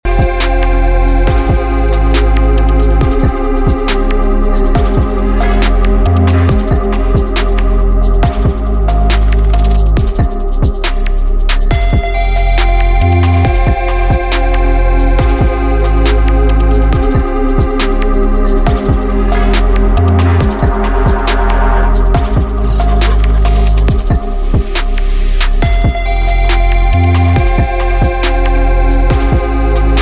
TOP >Vinyl >Grime/Dub-Step/HipHop/Juke
Instrumental